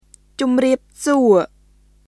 [チョムリアップ・スオ　cʊmriˑəp suˑo]